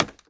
Minecraft Version Minecraft Version snapshot Latest Release | Latest Snapshot snapshot / assets / minecraft / sounds / block / bamboo / step4.ogg Compare With Compare With Latest Release | Latest Snapshot
step4.ogg